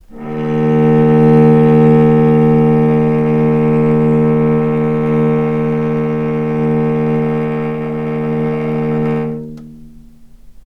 vc-D#2-mf.AIF